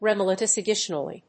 音節re・mil・i・ta・ri・za・tion 発音記号・読み方
/rìːmìləṭərɪzéɪʃən(米国英語)/